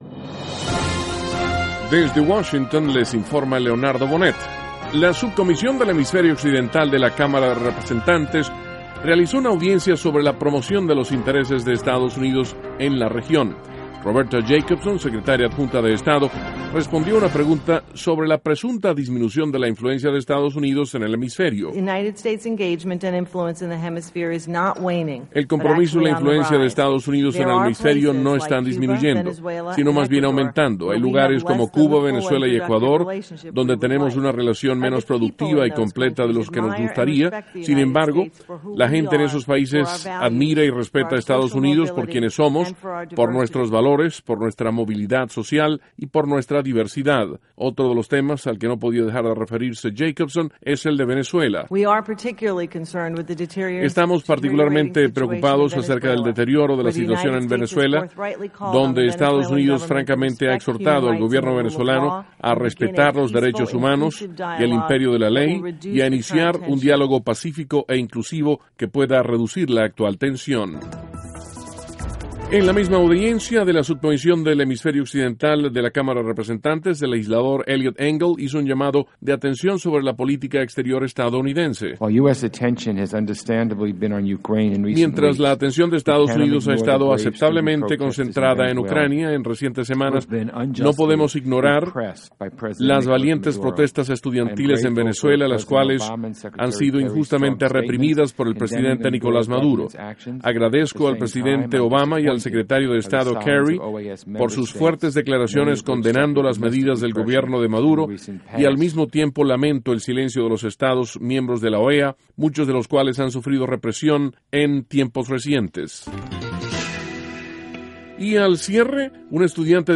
NOTICIAS - MIÉRCOLES, 9 DE ABRIL, 2014